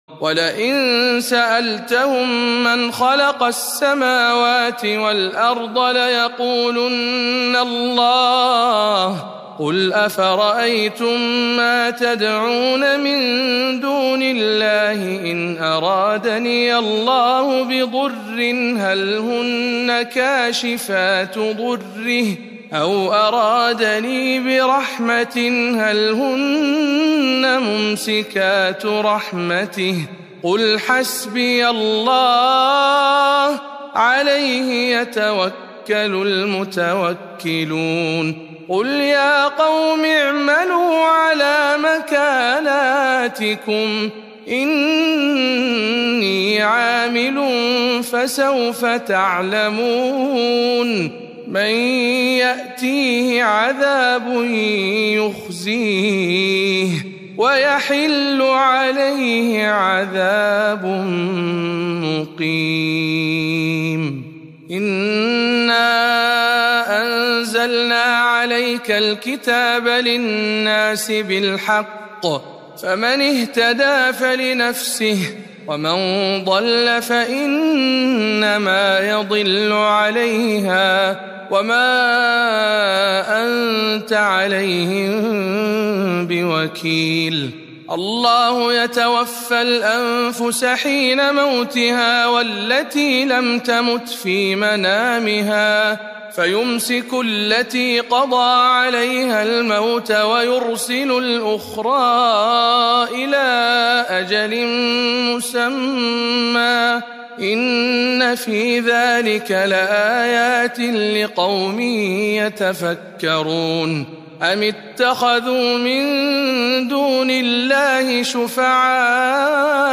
تلاوة مميزة من سورة الزمر